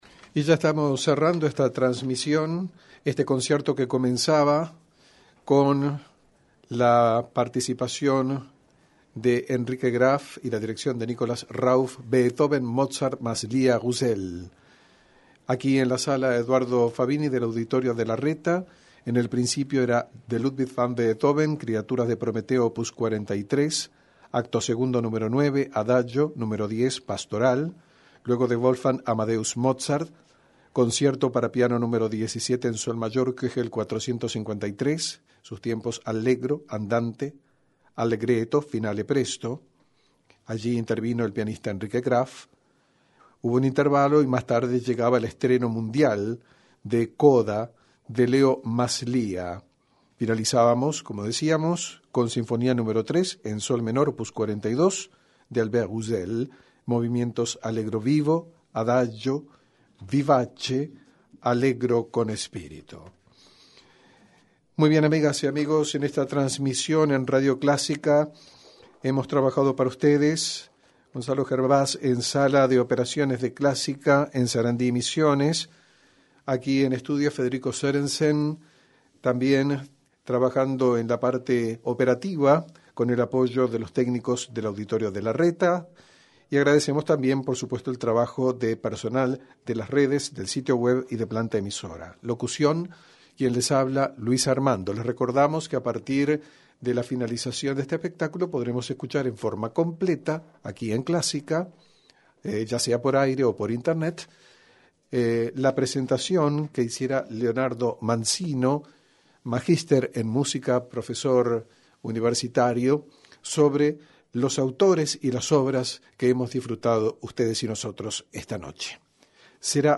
Un ecléctico concierto
piano